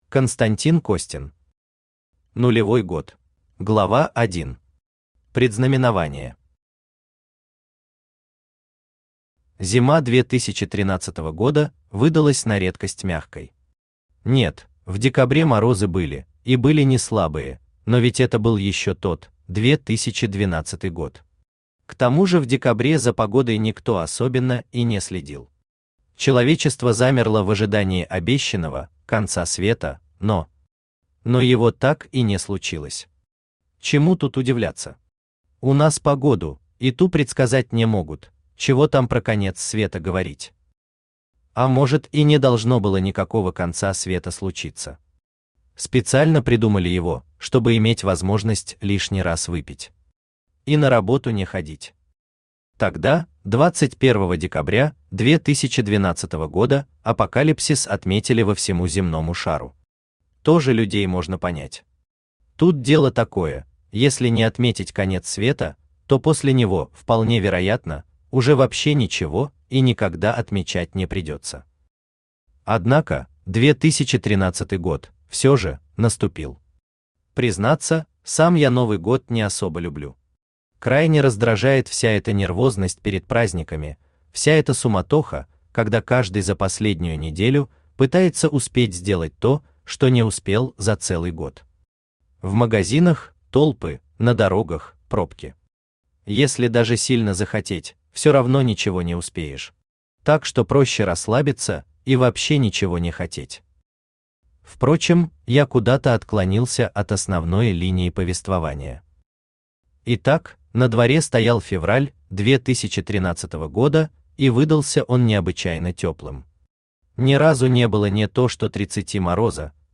Аудиокнига Нулевой год | Библиотека аудиокниг
Aудиокнига Нулевой год Автор Константин Александрович Костин Читает аудиокнигу Авточтец ЛитРес.